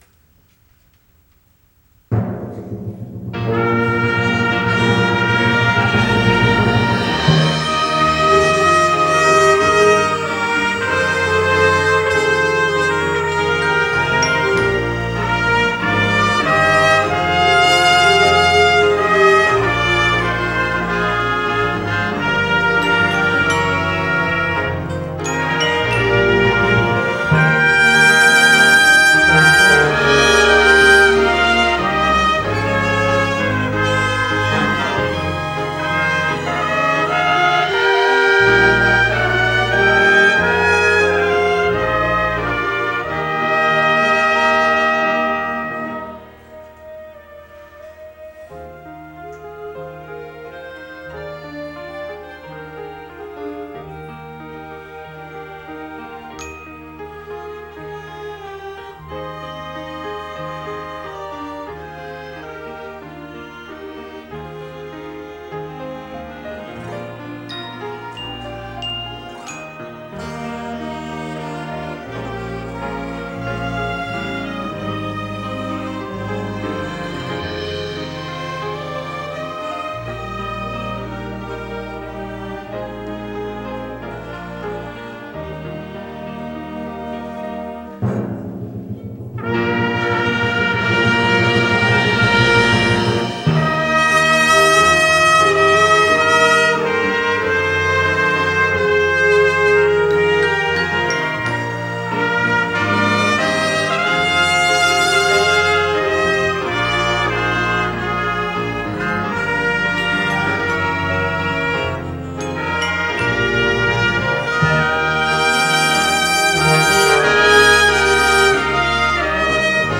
“My Faith Still Holds” ~ Faith Baptist Church Orchestra
morning-song-orchestra.mp3